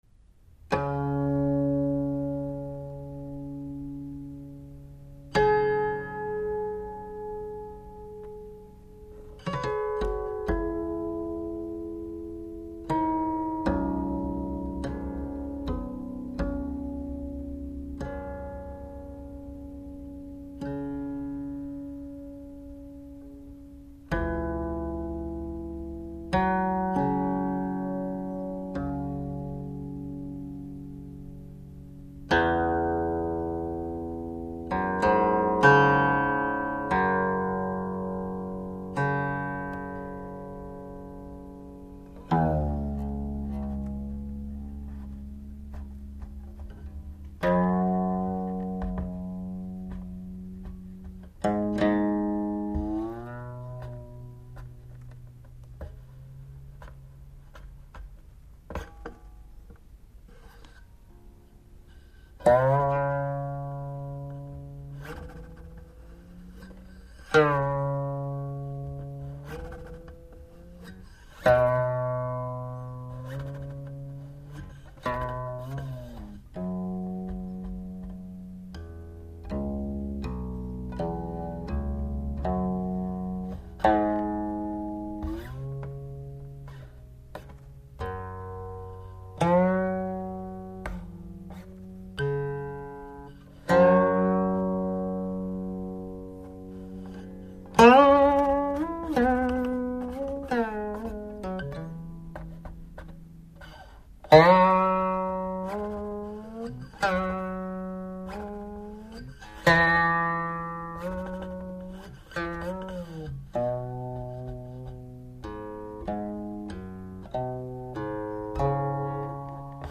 0160-古琴曲水仙操.wma